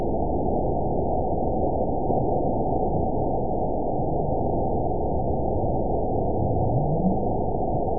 event 921971 date 12/23/24 time 23:30:00 GMT (8 months, 3 weeks ago) score 9.59 location TSS-AB02 detected by nrw target species NRW annotations +NRW Spectrogram: Frequency (kHz) vs. Time (s) audio not available .wav